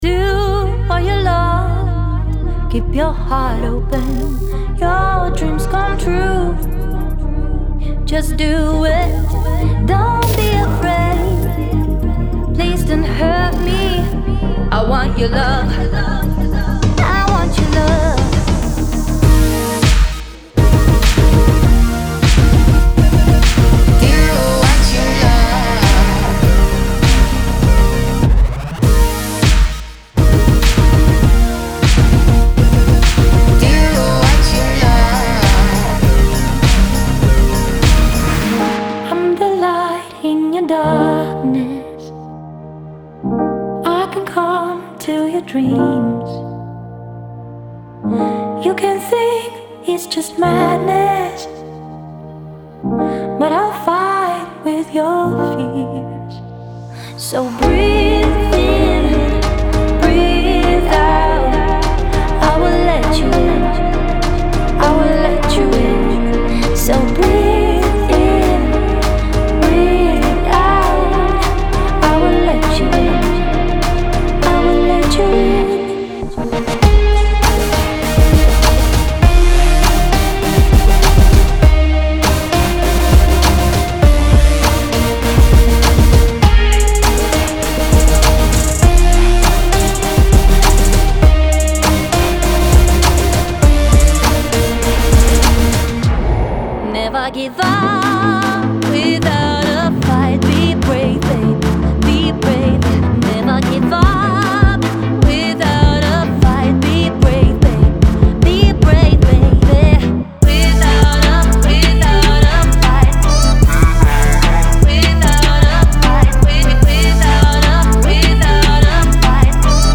括干，湿人声排骨以及经过额外处理的环。
·完全混合和掌握